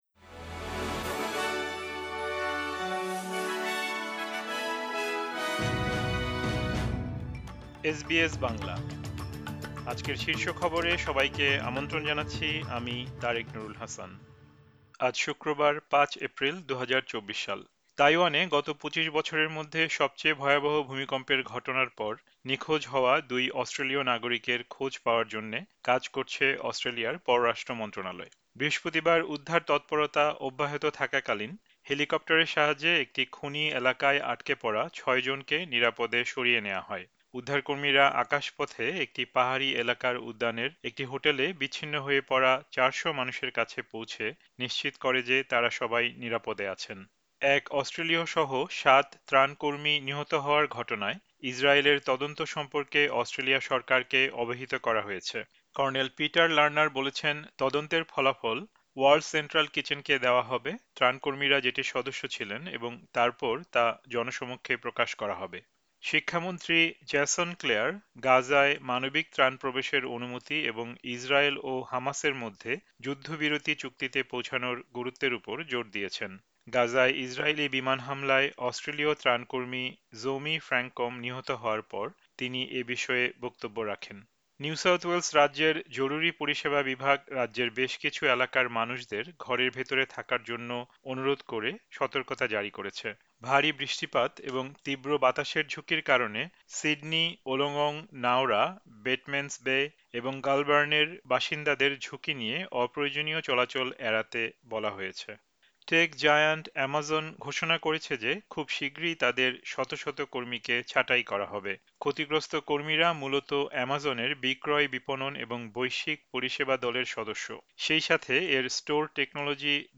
এসবিএস বাংলা শীর্ষ খবর: ৫ এপ্রিল, ২০২৪